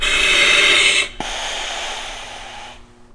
chot-breathe.mp3